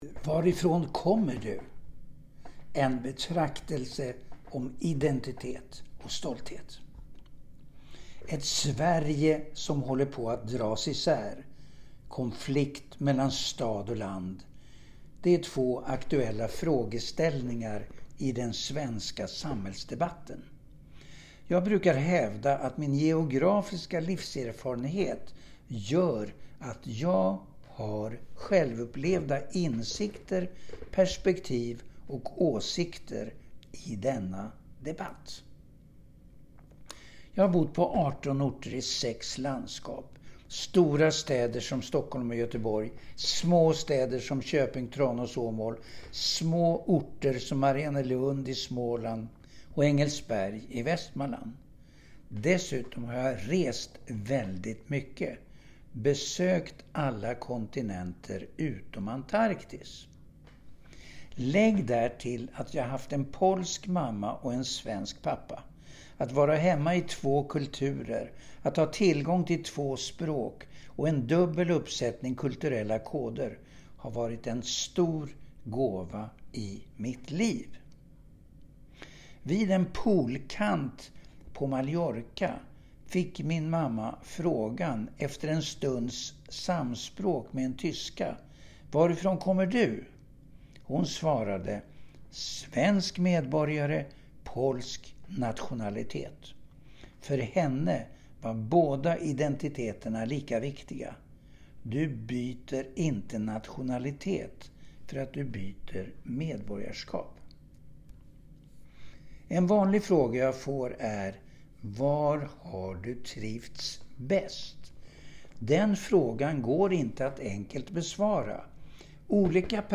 KRÖNIKA